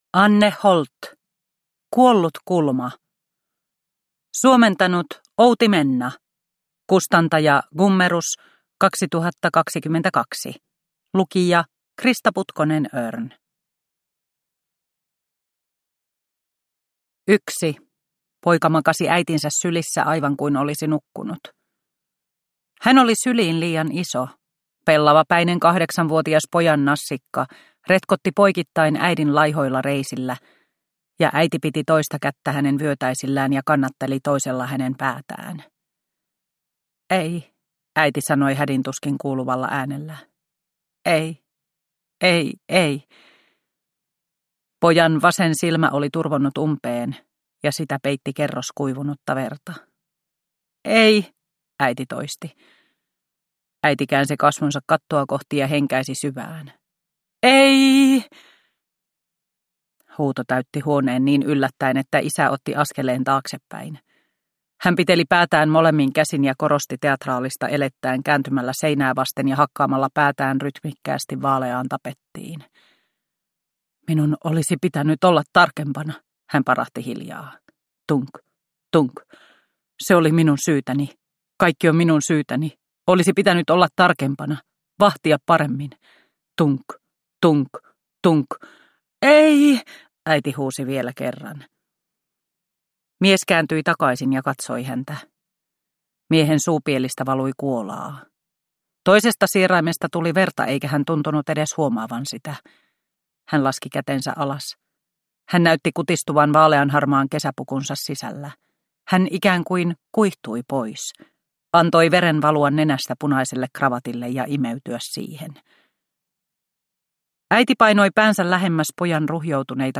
Kuollut kulma – Ljudbok – Laddas ner